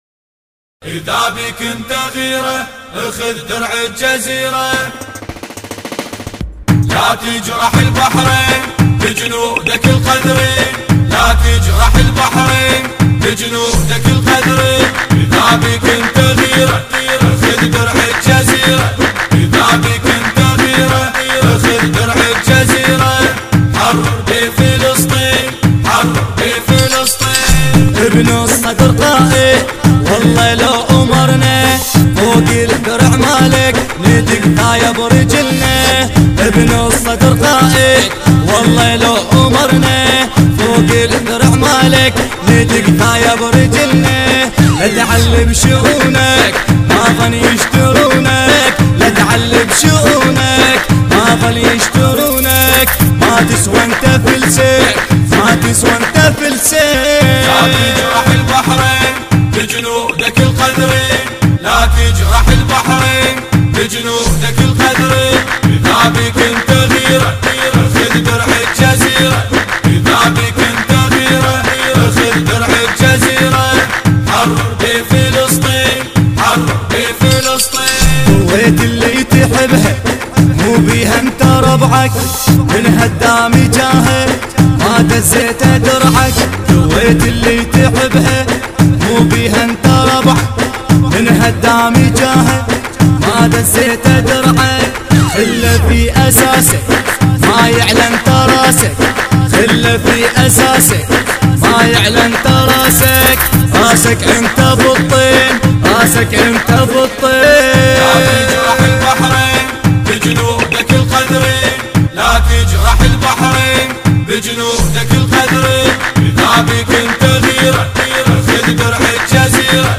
انشودة وطنية
أناشيد بحرينية